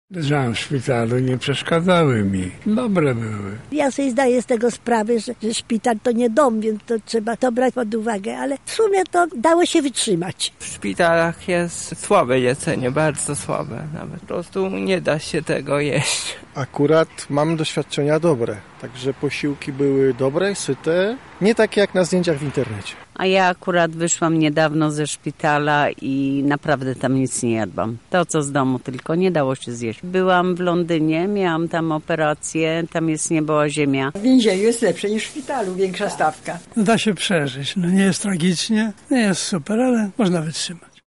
Zapytaliśmy mieszkańców Lublina o ich doświadczenia ze szpitalną dietą:
Sonda